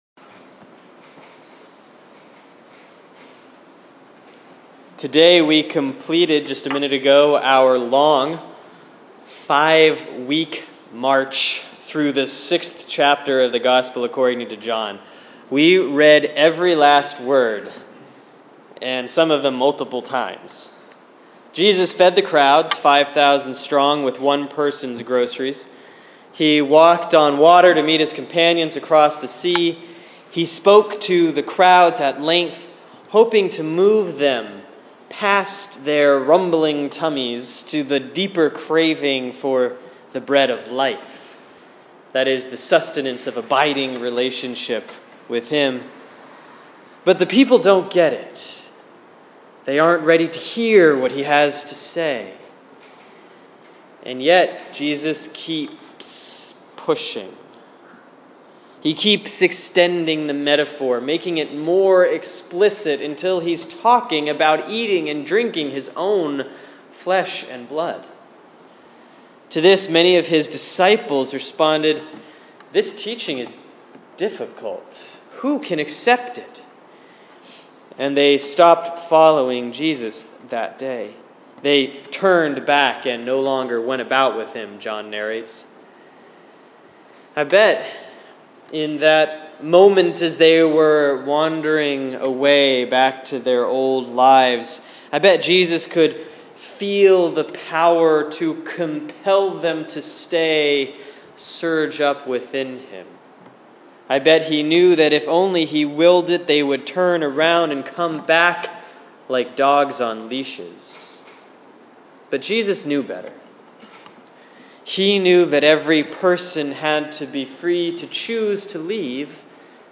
Sermon for Sunday, August 23, 2015 || Proper 16B || John 6:56-69; Joshua 24:1-2a, 14-18